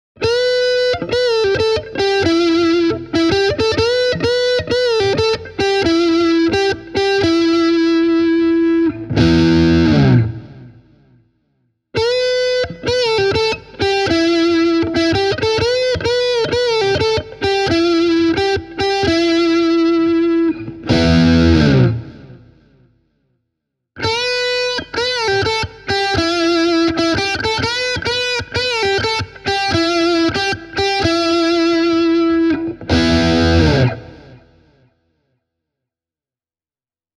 Akustisesti soitettuna ES-145G:n ja ES-138:n soundit ovat käytännössä identtisiä.
Myös vahvistettuna on vaikea huomata selkeitä eroja – Tokai ES-145G soi kuin mitä erittäin laadukkaalta puoliakustiselta sopii odottaa.